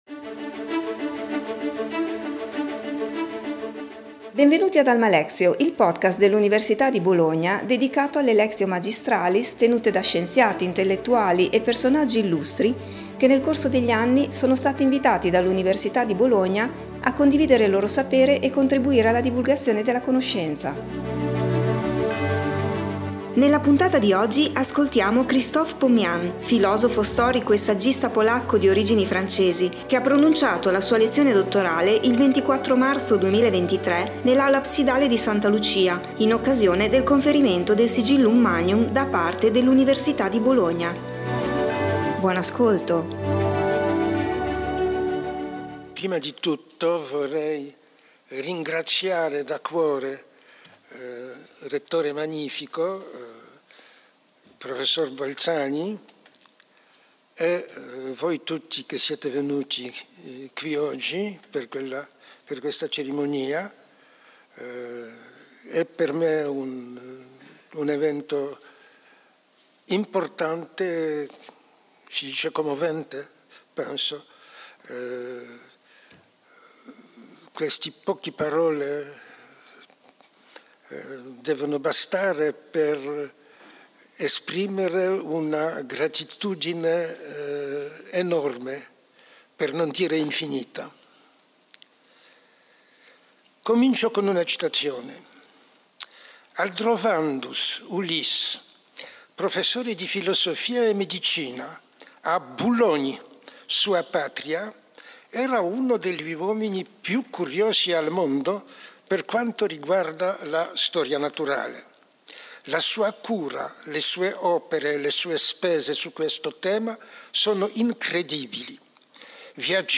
Krzysztof Pomian, filosofo, storico, e saggista polacco di origini francesi, è uno dei più influenti studiosi della storia del patrimonio culturale europeo. Ha pronunciato la sua lezione dottorale il 24 marzo 2023 nell’Aula Absidale di Santa Lucia in occasione del conferimento del Sigillum Magnum da parte dell'Università di Bologna.